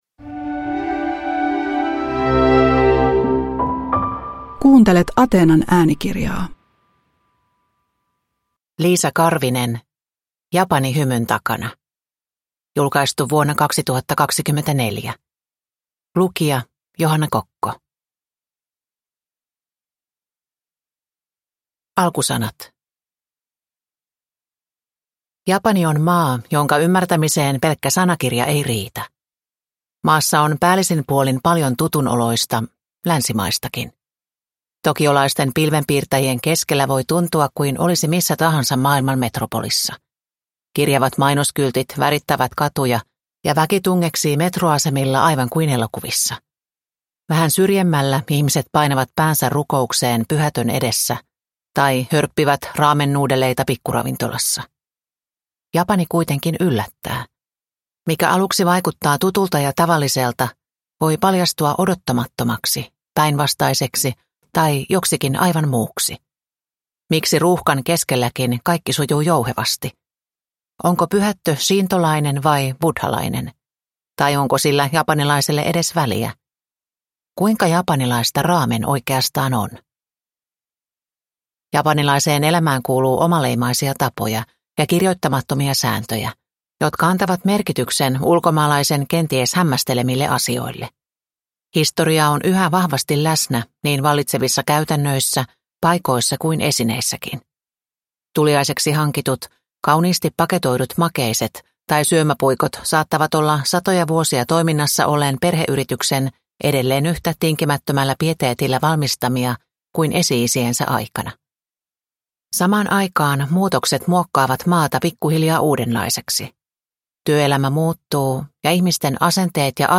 Japani hymyn takana – Ljudbok